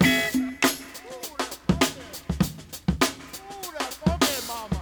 • 100 Bpm Modern Breakbeat E Key.wav
Free drum beat - kick tuned to the E note. Loudest frequency: 2544Hz
100-bpm-modern-breakbeat-e-key-ceU.wav